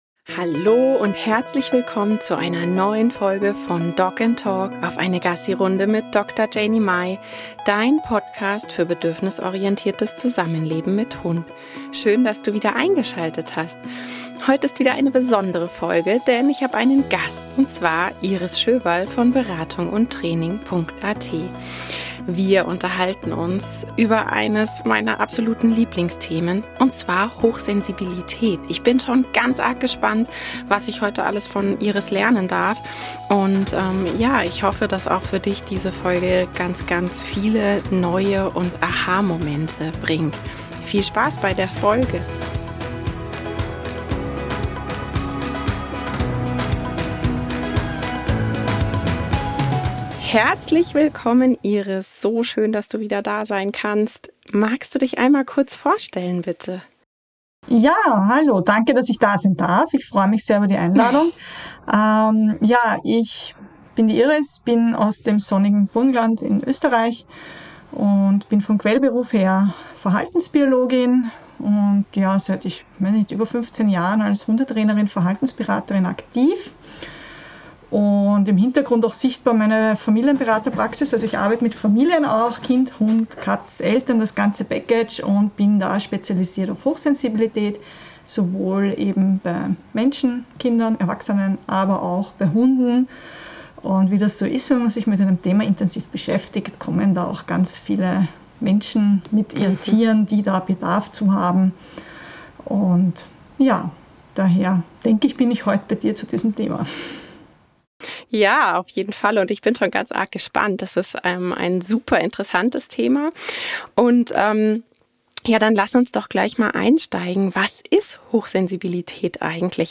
#20 - Hochsensibilität - Interview